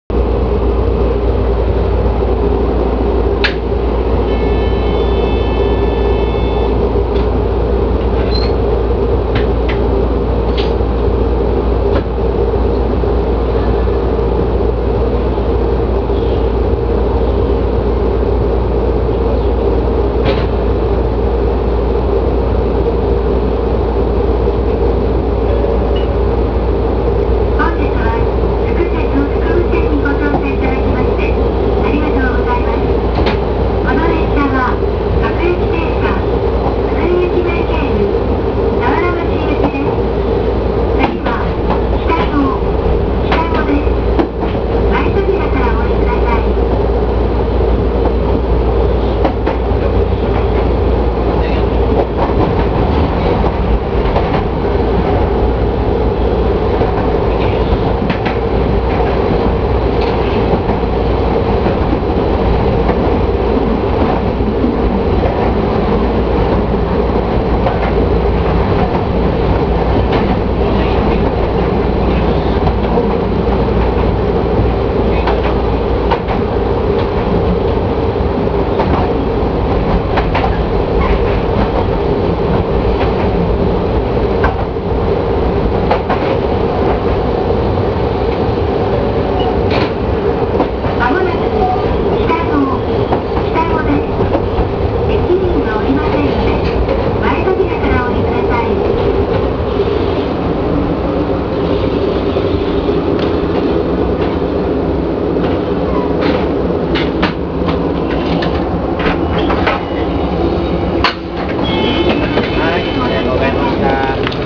800形走行音
【福武線】越前武生→北府（1分54秒：622KB）
夏場でクーラーフル稼働な時に乗ったのも理由の１つなのかもしれませんが、正直モーター音は乗っていても全くと言っていいほど聞こえません。一応は、IGBTのはずです。